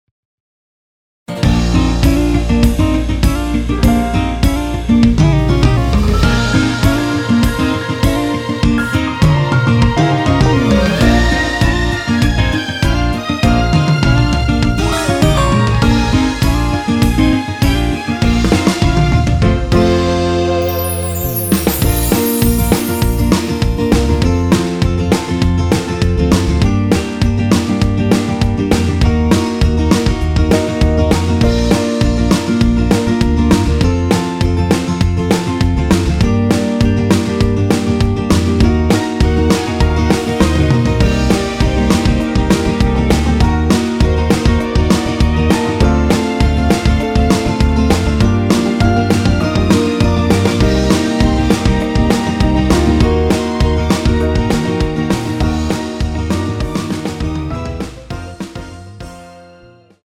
원키에서(+4)올린 MR입니다.
Bb
◈ 곡명 옆 (-1)은 반음 내림, (+1)은 반음 올림 입니다.
앞부분30초, 뒷부분30초씩 편집해서 올려 드리고 있습니다.
중간에 음이 끈어지고 다시 나오는 이유는